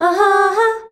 AHAAA   F.wav